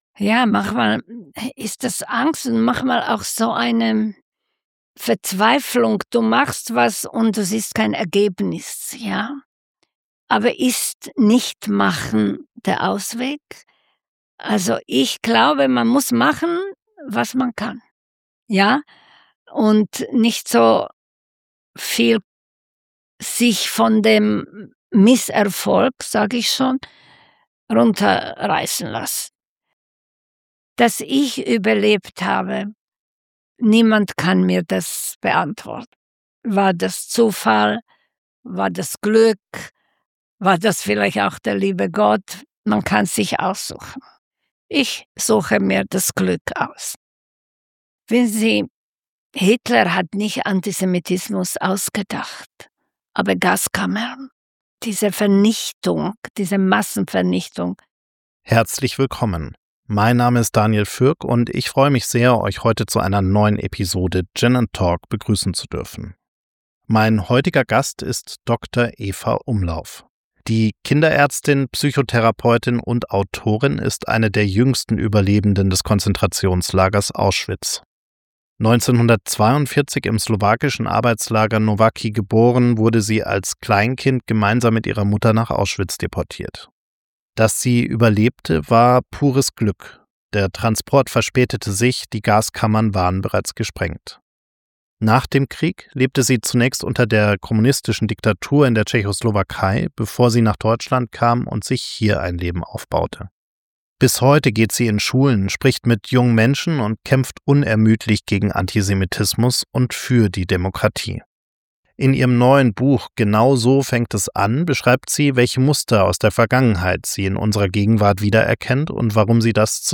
Ein Gespräch über Resignation, Entmenschlichung und die Frage, warum Demokratien leise sterben.